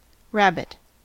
rabbit (2).mp3